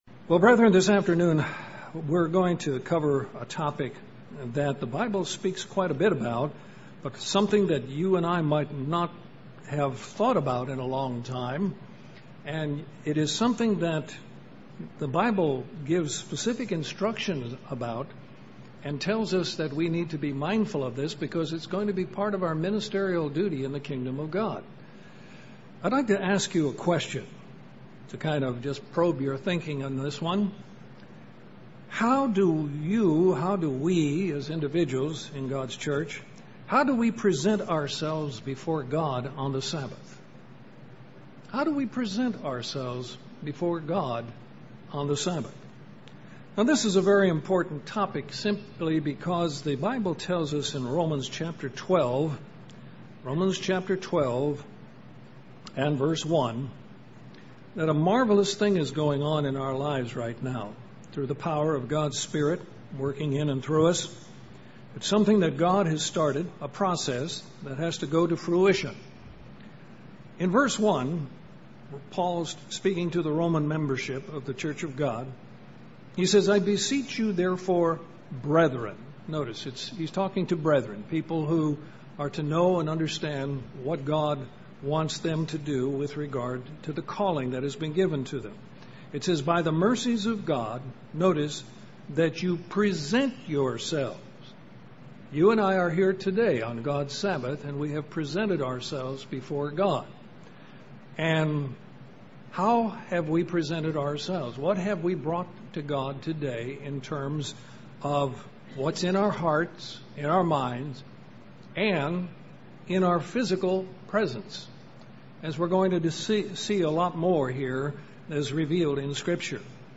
This sermon addresses the importance of honoring God in our style of dress.